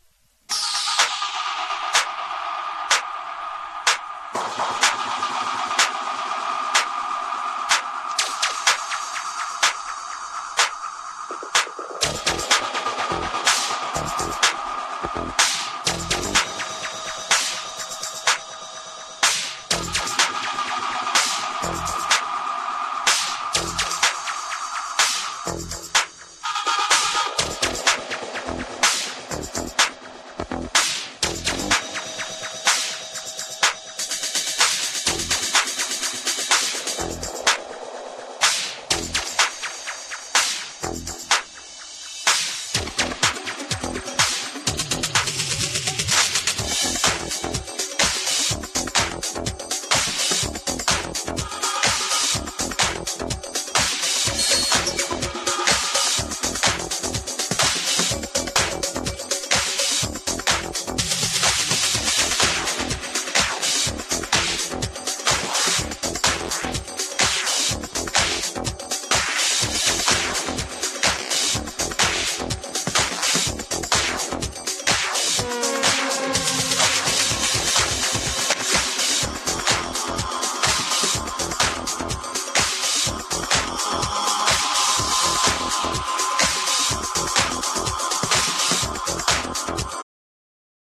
ニューウェーヴィーなエレクトロ・ハウス